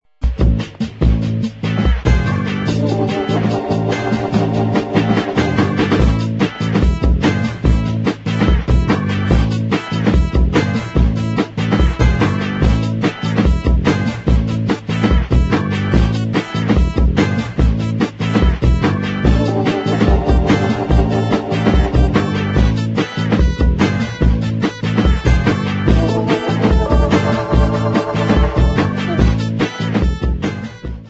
1999 nervous fast instr.